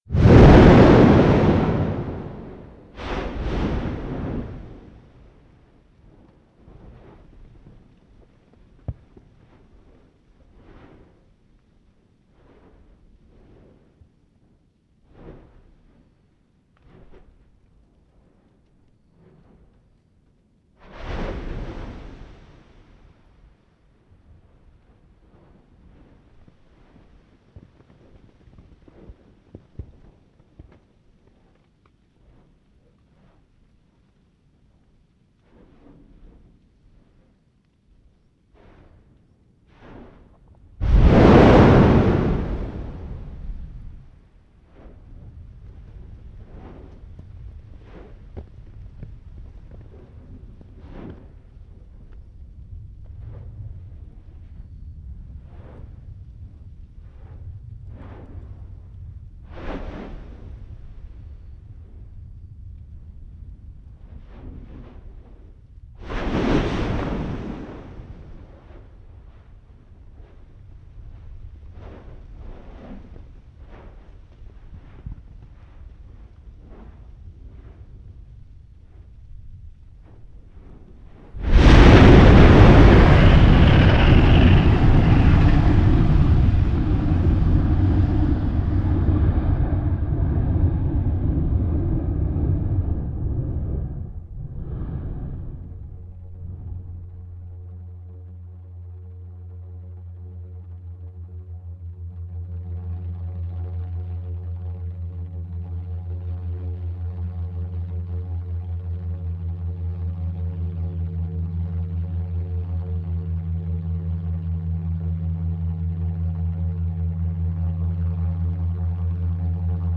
Field Recording Series